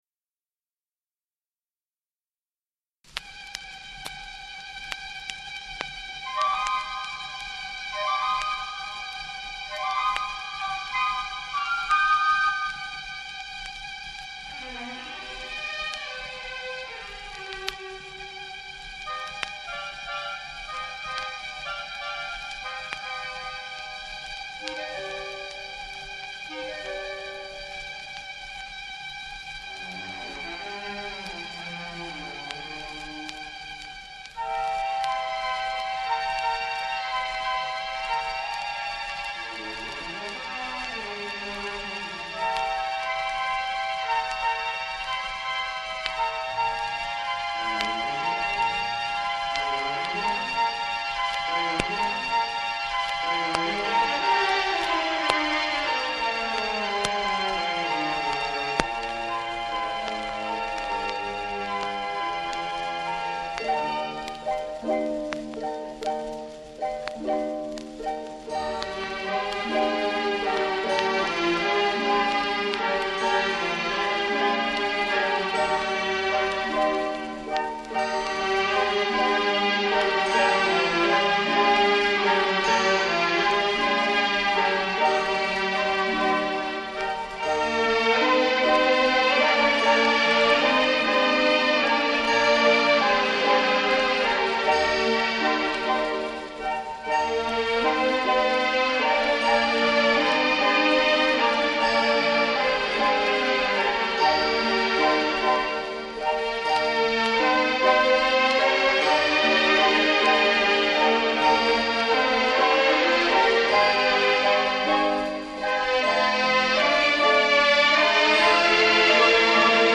2. Ideal dynamic range plus clarity and brilliance.